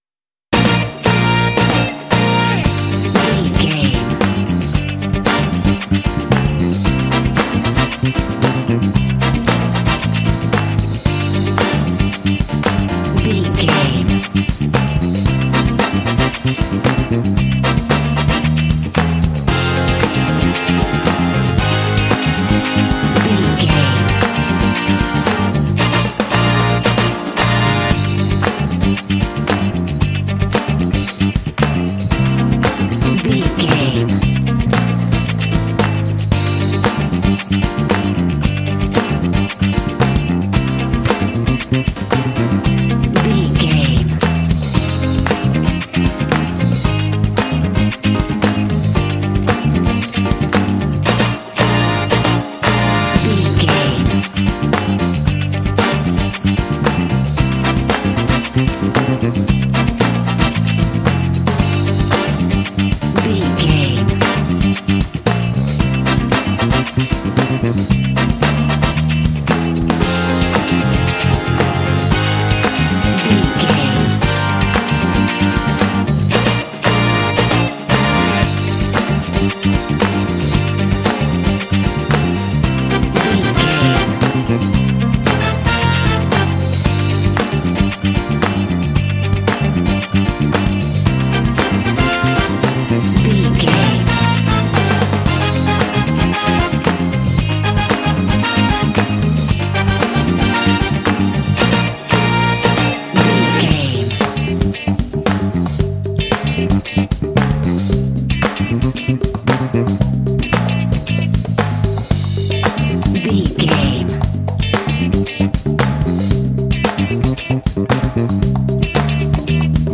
Uplifting
Aeolian/Minor
funky
groovy
bright
lively
energetic
bass guitar
electric guitar
brass
piano
drums
percussion
strings
Funk
jazz